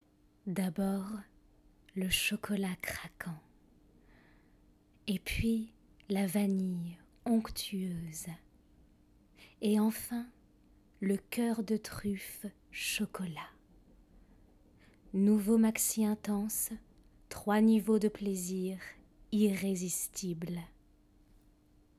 Maquette pub (voix sensuelle)